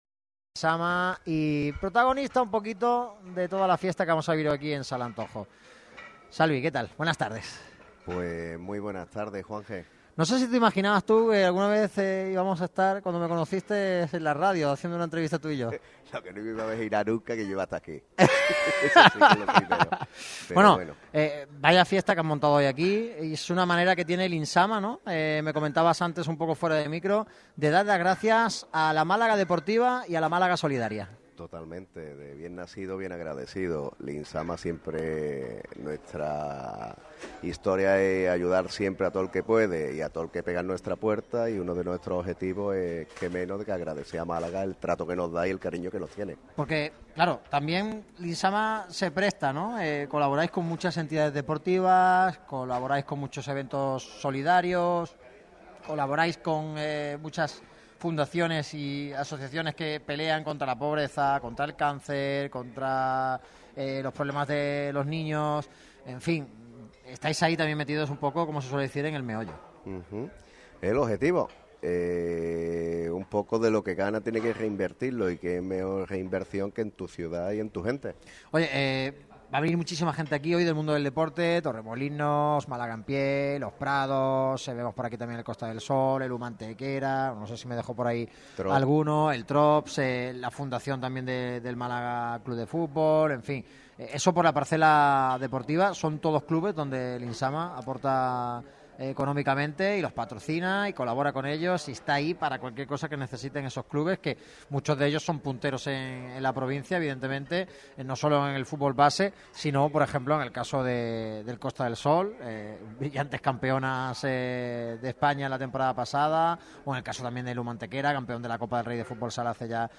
En el día de hoy, Radio Marca Málaga visita la Sala Antojo, situada en Calle San Loreno,25 en el Soho, Málaga capital.